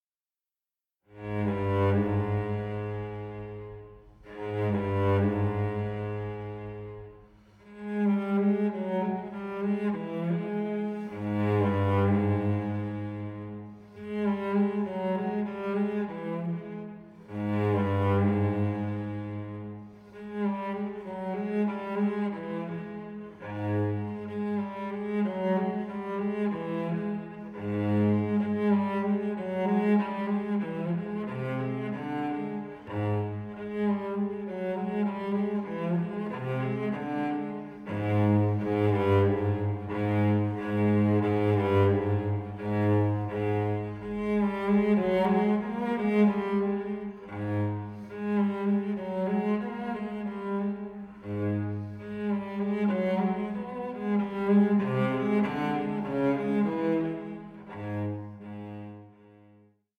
solo cello